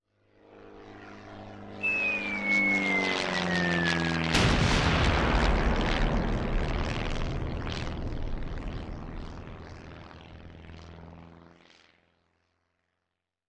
Caída cómica de una avioneta
avioneta
cómico
Sonidos: Especiales
Sonidos: Transportes